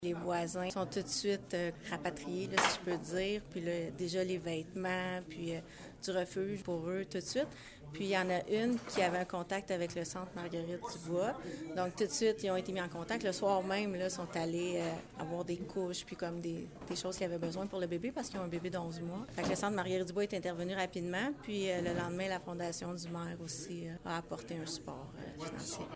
La conseillère du district Mont-Soleil, Tatiana Contreras a souligné en début de séance de lundi soir la rapidité de la communauté à apporter son soutien :